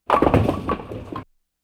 Bowling - Strike.wav